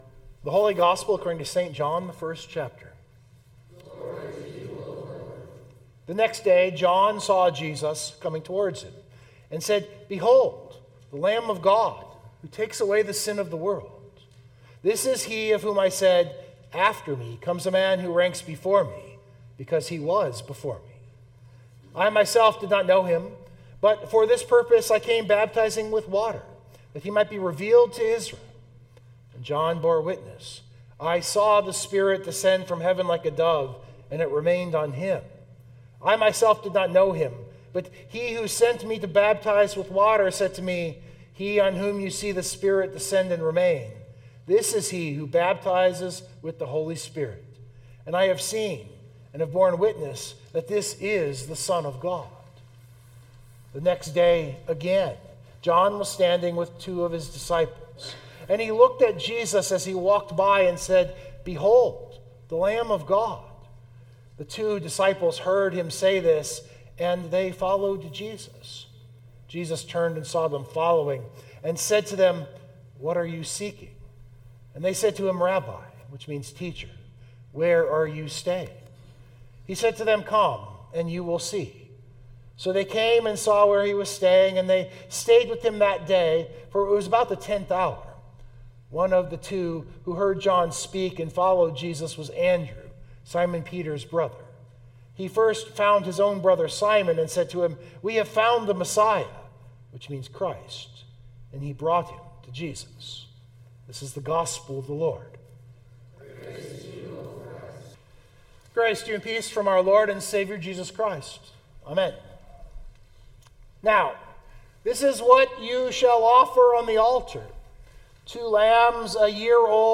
Text: John 1:43-51 Full Sermon Draft